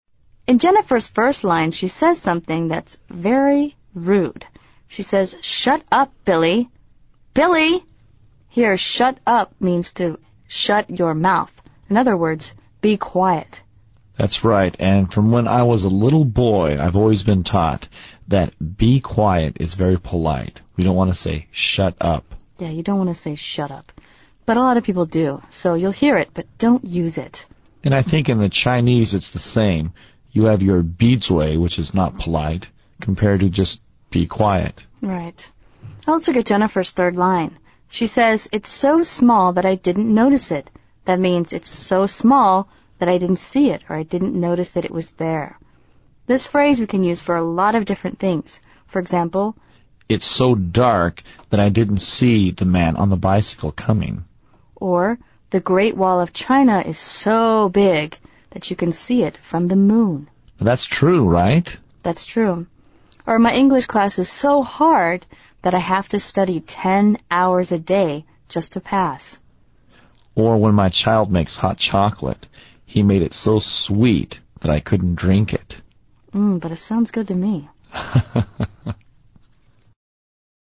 英文解释